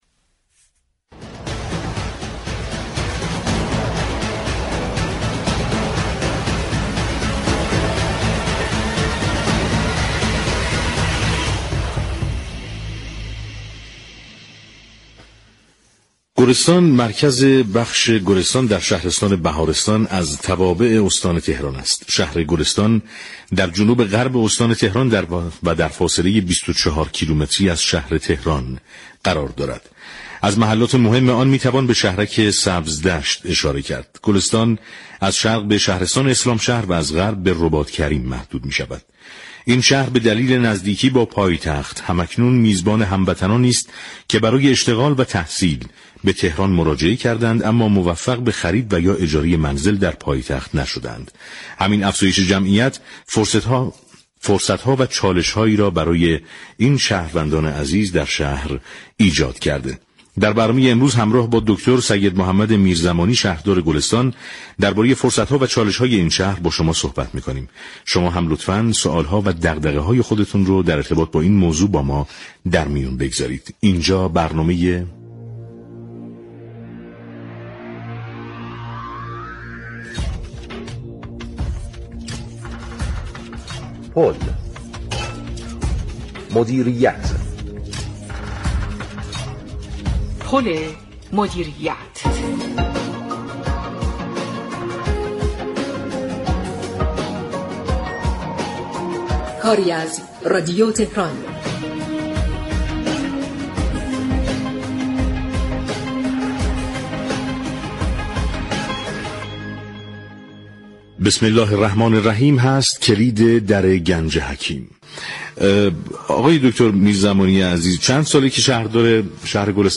در همین راستا برنامه 2 اسفنددر ارتباط تلفنی با سیدمحمد میرزمانی شهردار گلستان با موضوع فرصت‌ها و چالش‌های این شهر بر روی آنتن رادیو تهرا